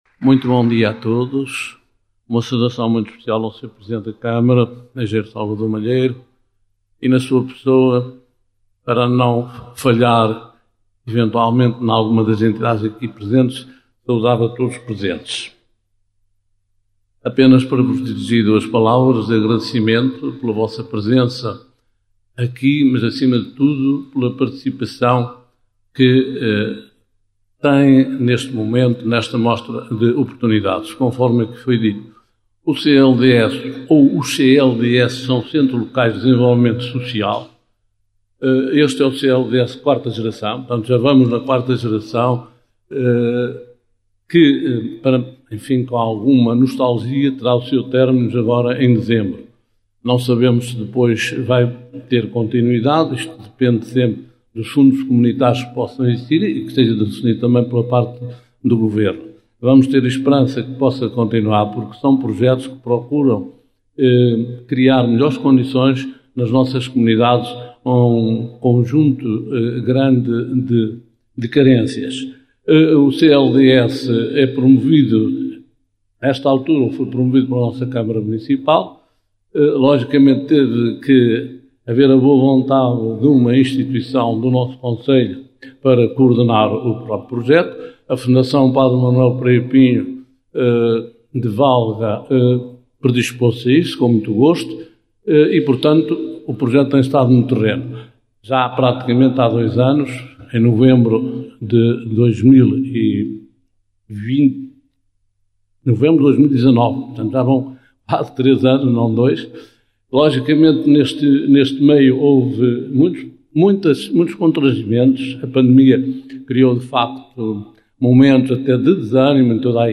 A Rádio AVfm esteve presente na Sessão de Abertura, registando o momento: